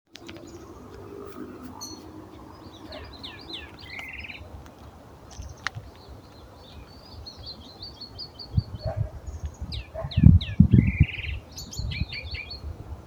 Pepitero Gris (Saltator coerulescens)
Nombre en inglés: Bluish-grey Saltator
Fase de la vida: Adulto
Localización detallada: Eco Área Avellaneda
Condición: Silvestre
Certeza: Vocalización Grabada